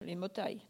Localisation Bois-de-Céné
Enquête Arexcpo en Vendée
Catégorie Locution